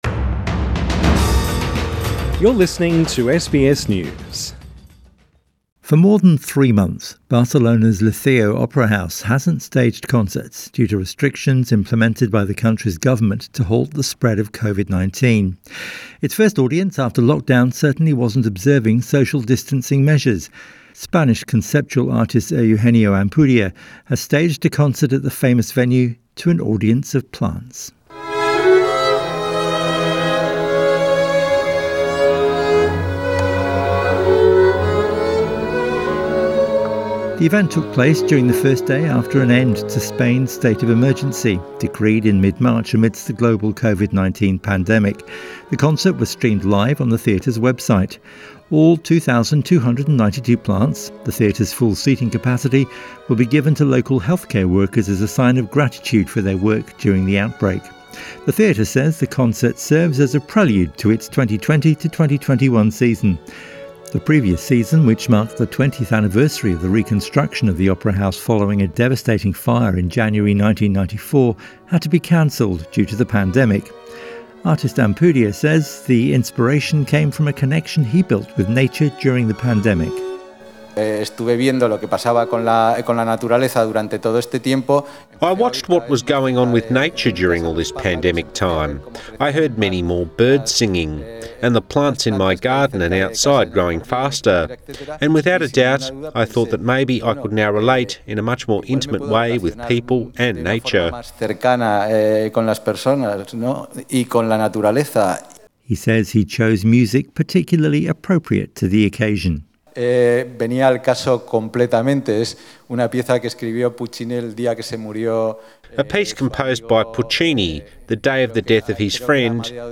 A string quartet performs in front of more than 2,200 nursery plants in Barcelona, Spain Source: AAP